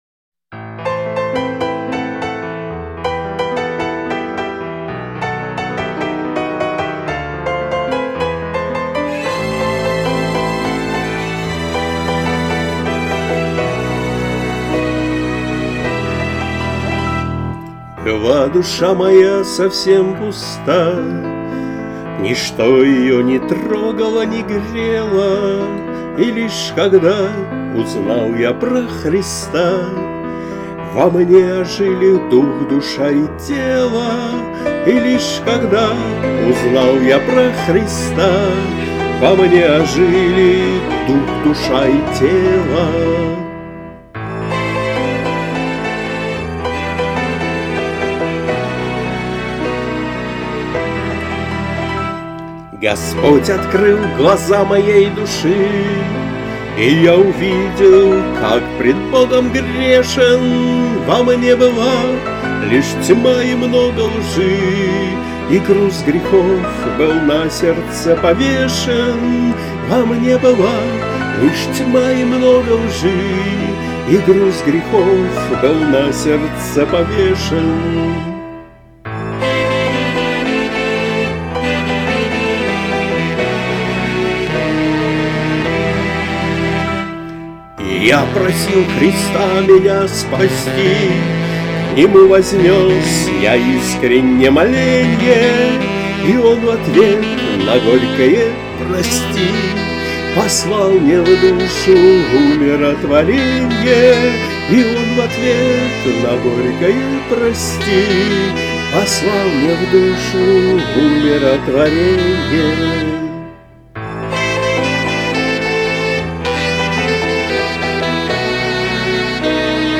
Христианские музыкальные песни